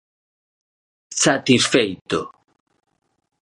Pronunciato come (IPA) [satisˈfei̯tʊ]